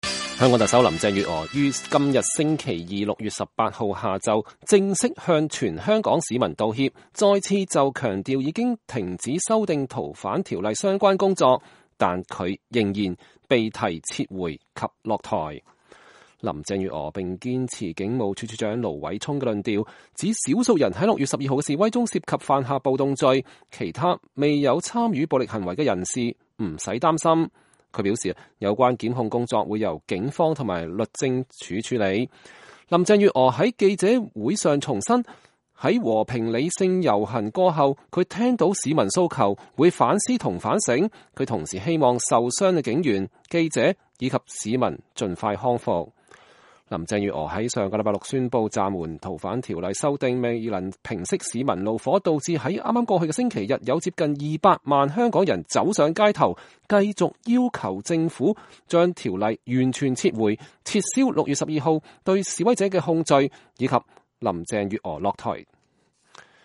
林鄭月娥在記者會上重申﹐在和平理性遊行過後，她聽到了市民的訴求，會反思和反醒。